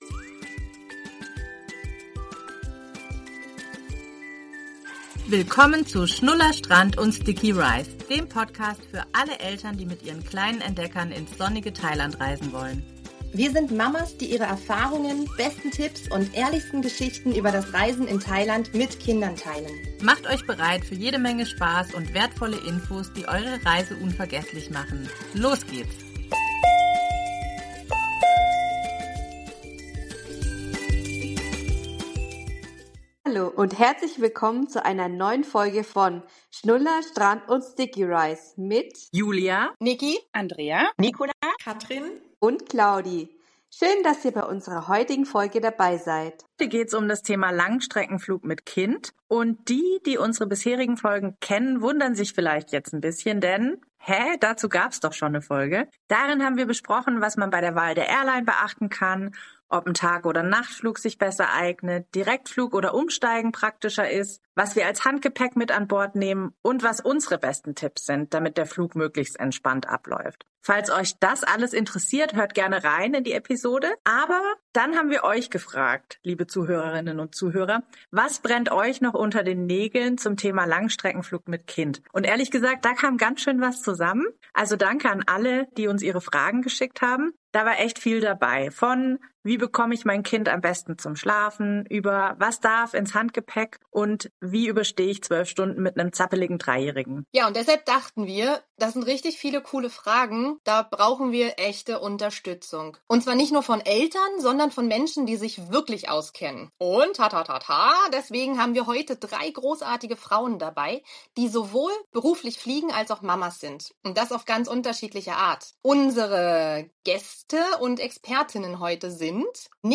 #010 - Interview Special: Flugfacts von Profis ~ Schnuller, Strand & Sticky Rice Podcast
Ein Gespräch voller Know-how, persönlicher Erfahrungen und Tipps, die euch garantiert weiterhelfen.